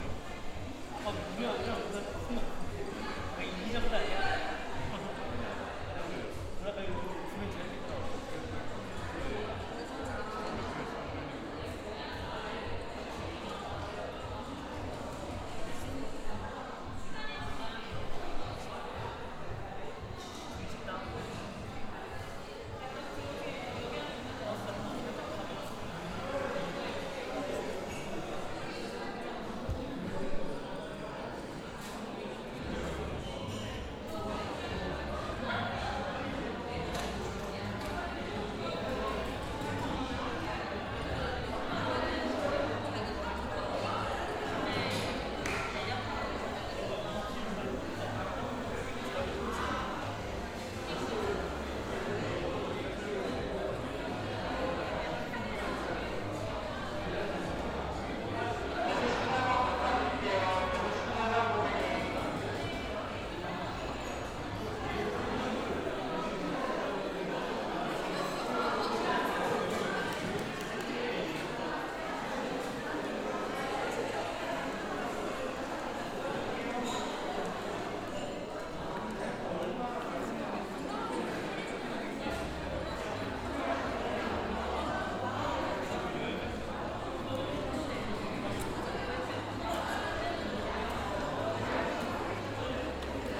카페시끄러움 멀리서.mp3